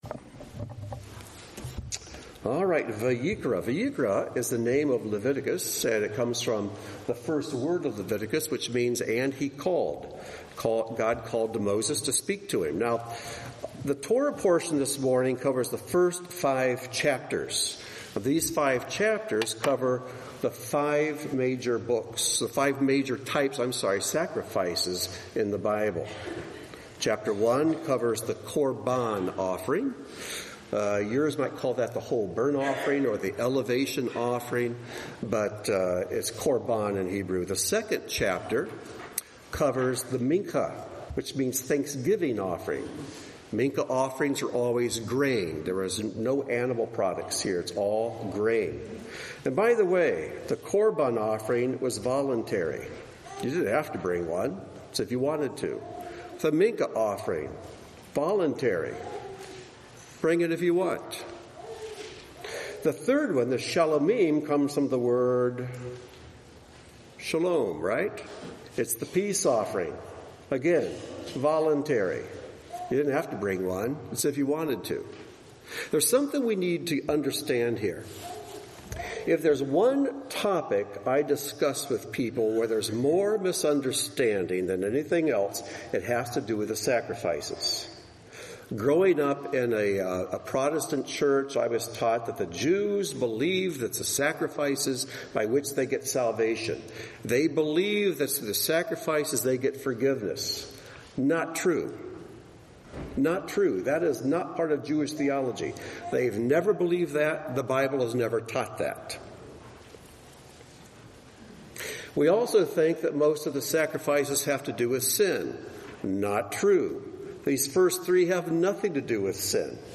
Join Beth Tikkun for this year's Parshah Vayikra teaching; we focus on seeing the sacrifices as examples for how we can be living sacrifices.